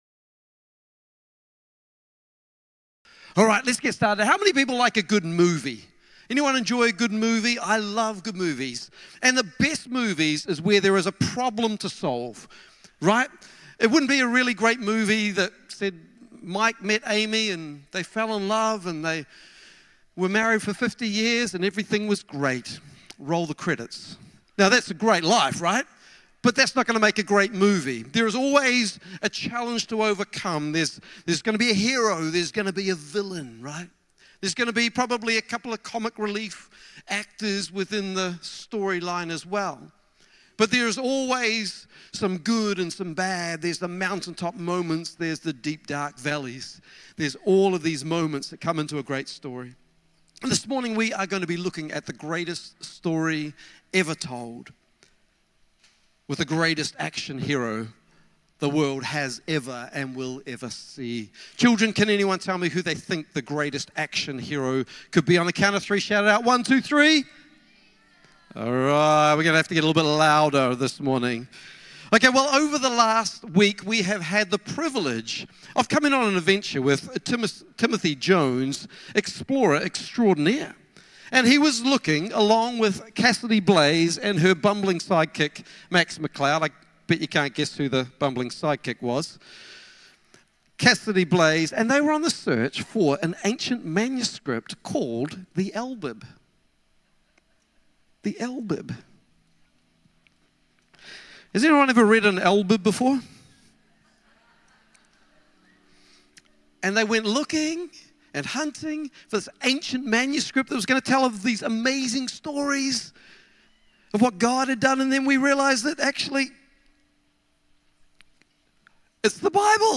Rediscover Church Exeter | Sunday Messages Welcome To The Jungle